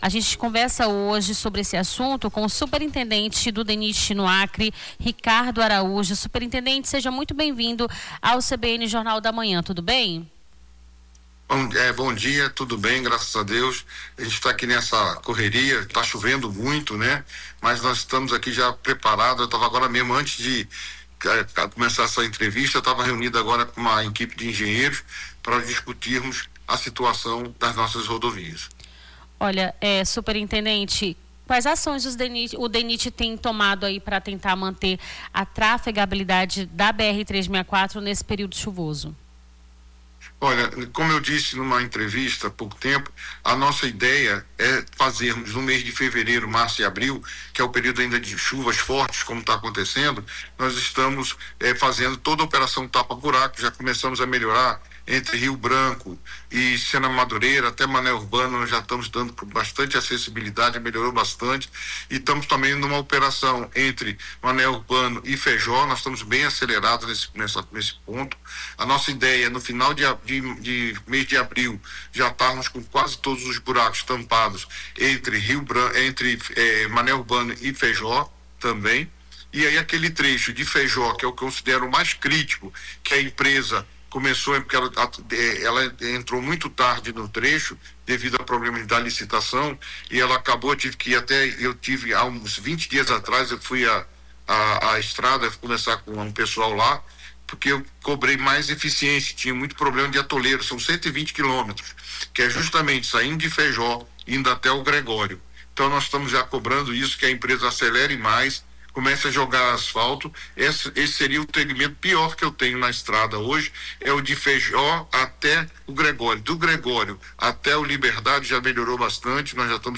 Na manhã desta segunda-feira, 30, conversamos com o superintendente do DNIT no Acre, Ricardo Araújo, sobre os problemas de infraestrutura que a rodovia enfrenta.